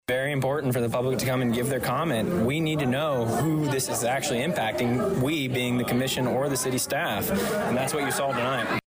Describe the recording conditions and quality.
A capacity crowd of about 50 filled the City Hall Chambers; with 20 residents, some of them speaking more than once, all speaking against the Summit Ridge Energy proposal, which would be on land owned by Martin Farms out of Indiana.